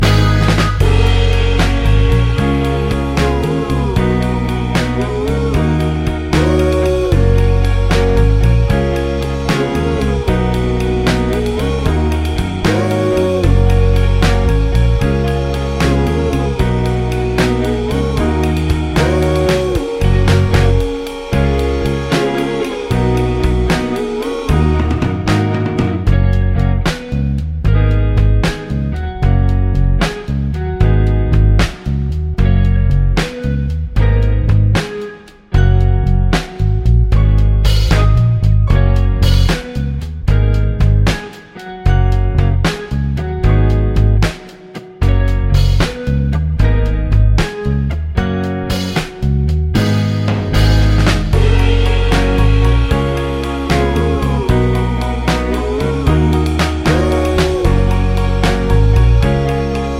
no Backing Vocals Blues 3:35 Buy £1.50